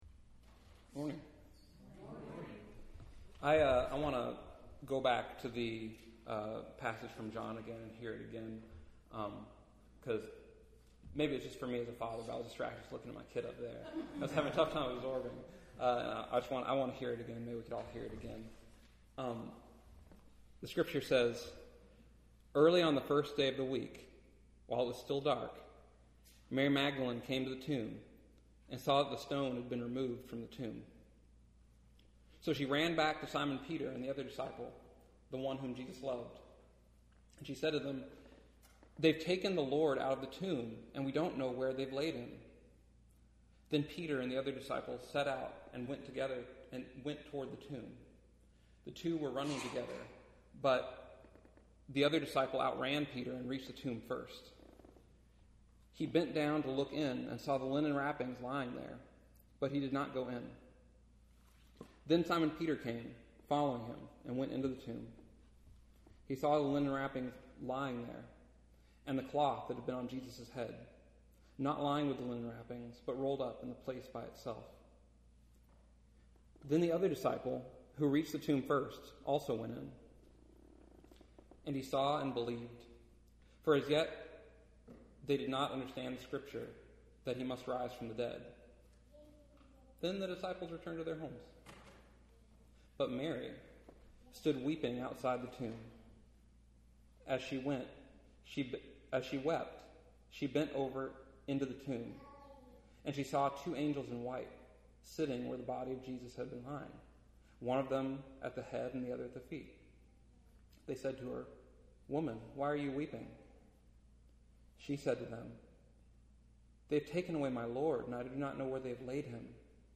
easter-sunday-2018.mp3